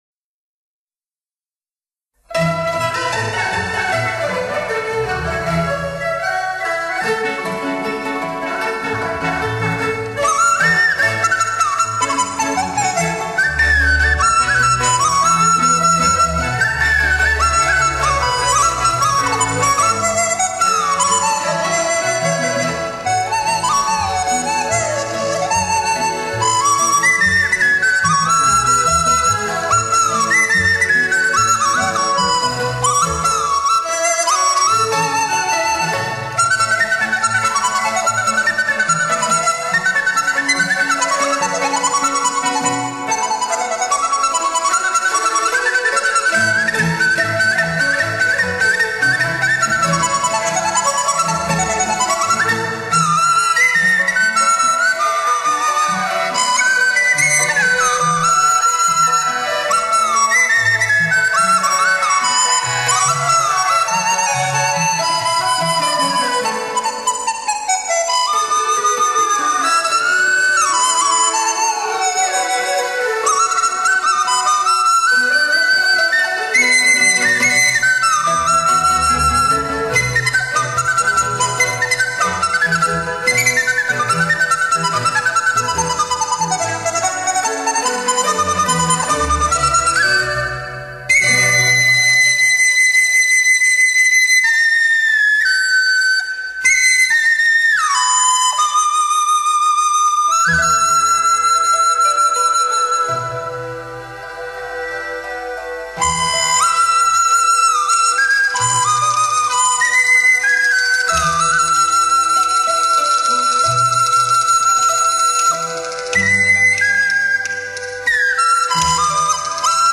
百鸟朝凤 吹管乐